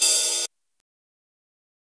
Metro Dark Open Hat.wav